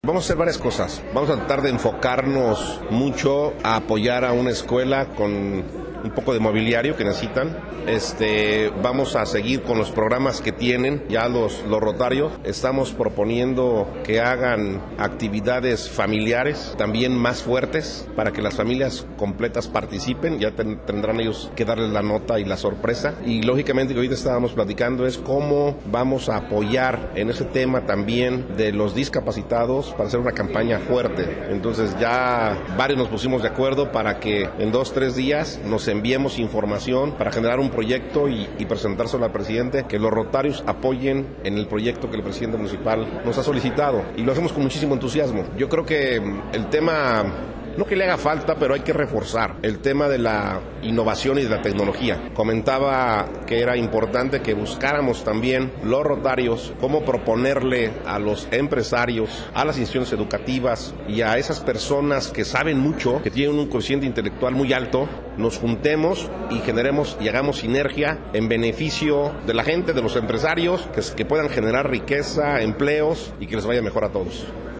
Daniel Zarate Estrada presidente municipal de Numarán señaló que ya se tiene proyectos elaborados para empezar a trabajar en el municipio, el alcalde señaló que el gobierno del estado tiene olvidado a Numarán pero dijo que viene cosas buenas para la localidad.